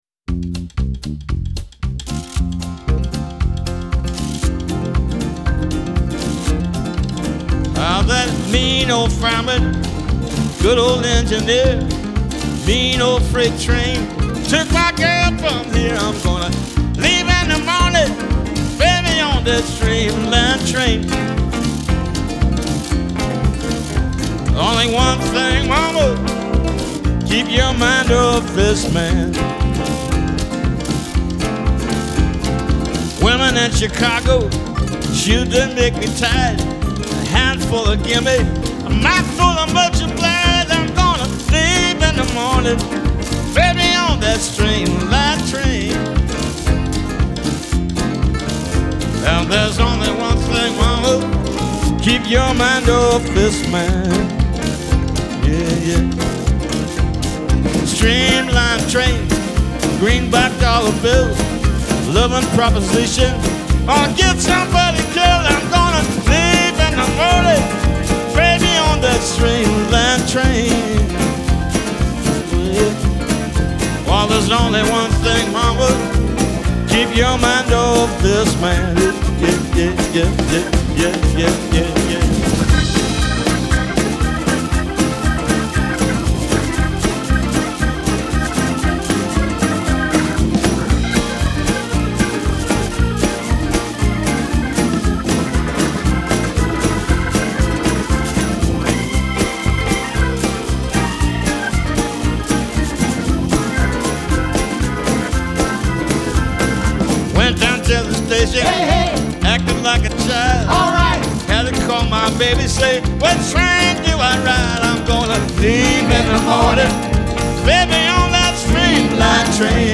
Genre : Blues